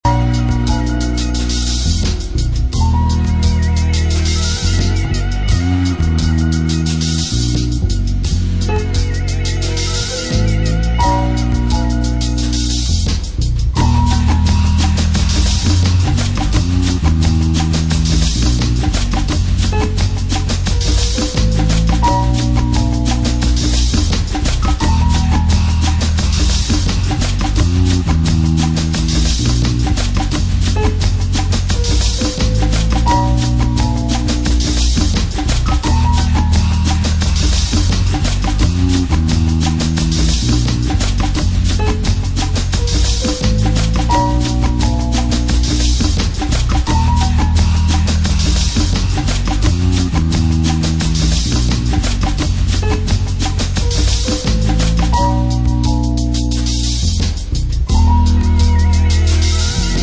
Breakbeat
Drum n bass, Future jazz